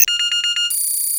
combine_button_locked.wav